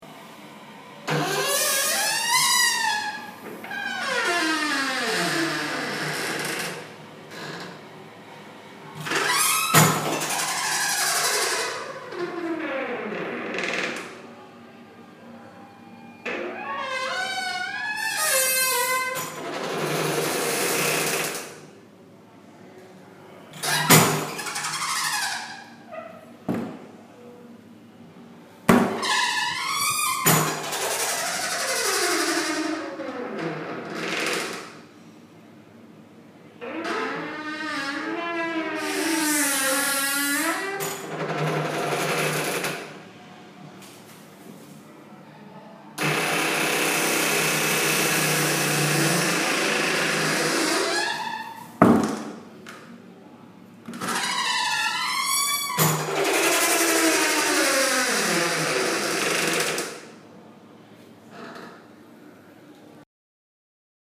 Спойлер: Naturel Библиотеки...Я в Брисбэне зашёл в туалет в пабе. Дверь открыл и чуть в штаны не навалил от страха, вот это звук...голимый аналог и никакого морфинга Вложения Brisbane Pab.mp3 Brisbane Pab.mp3 1,2 MB · Просмотры: 317